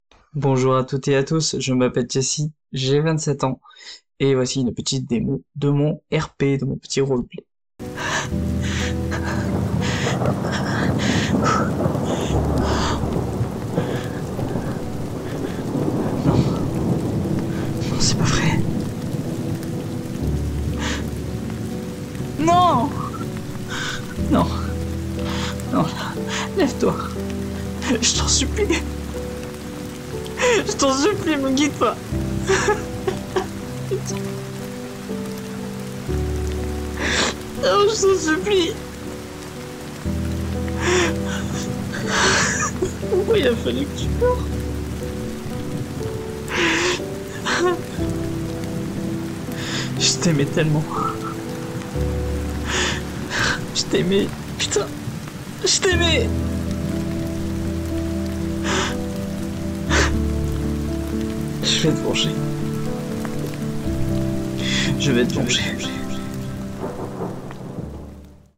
Bandes-son
Voix off
16 - 28 ans